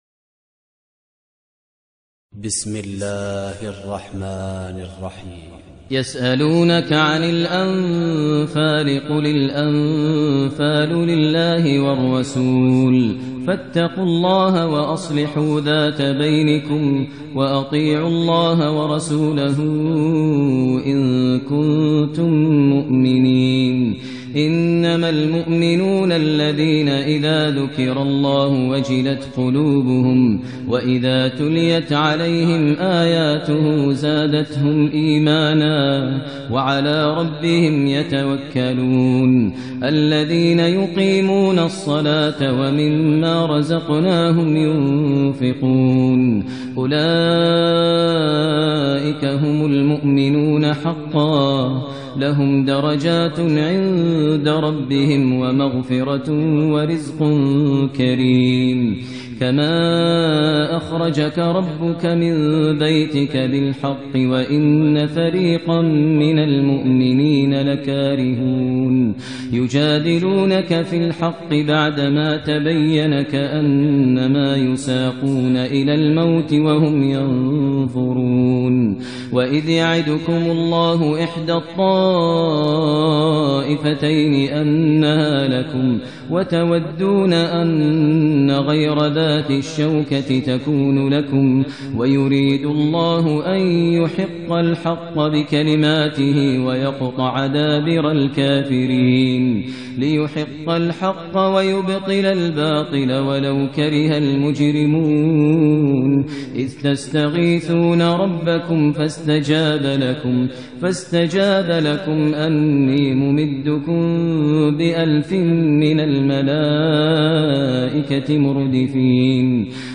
ترتیل سوره انفال با صدای ماهر المعیقلی